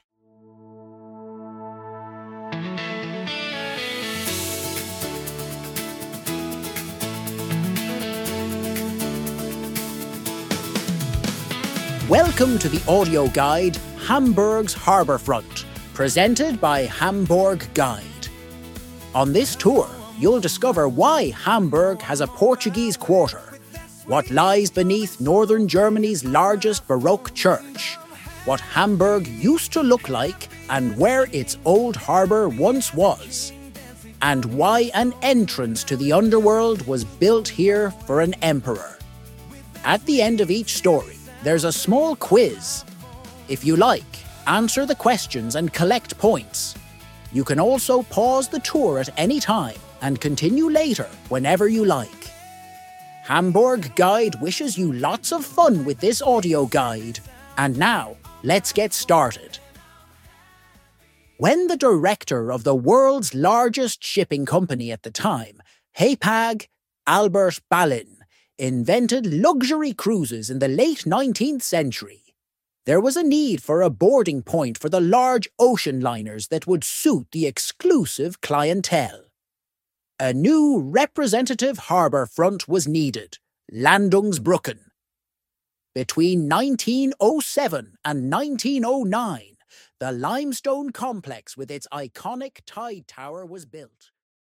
Hamburg’s Harbour Front – self-guided audio tour
EN-Preview-Landungsbrucken-Fin.mp3